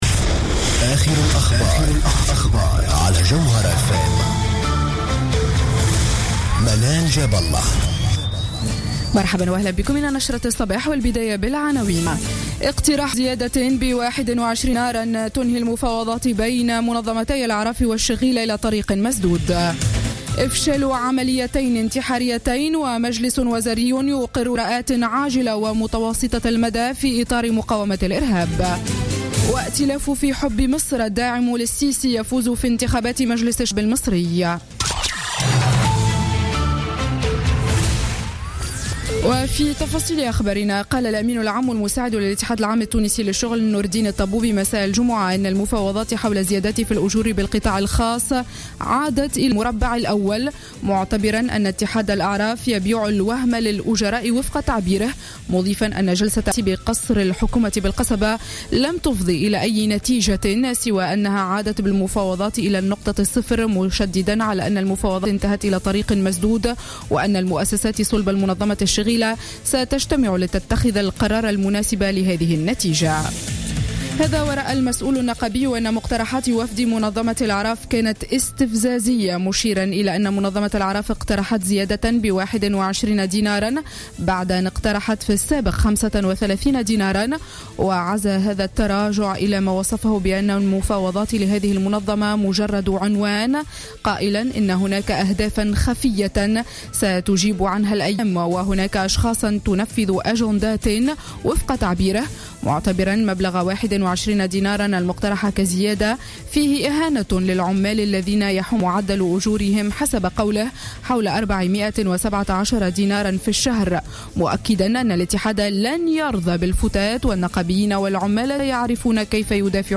نشرة أخبار السابعة صباحا ليوم السبت 5 ديسمبر 2015